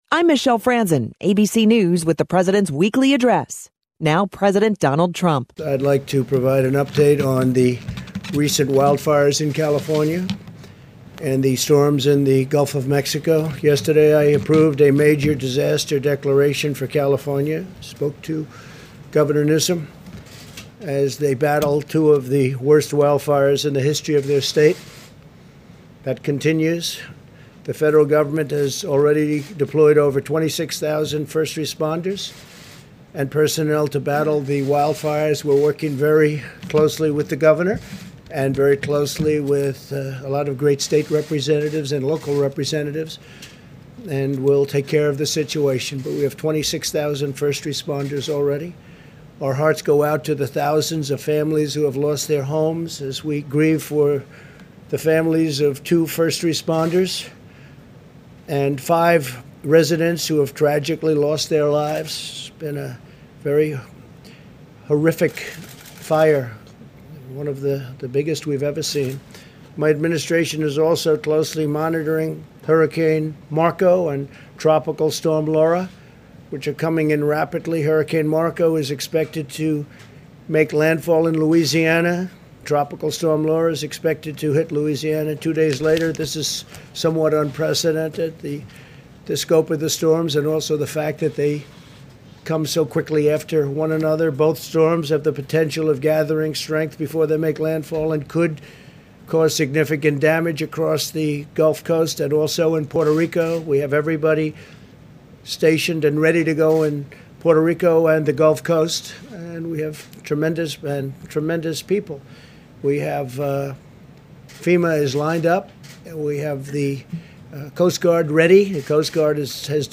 Trump is Monday’s morning’s “Newsmaker of the Day” on KVML.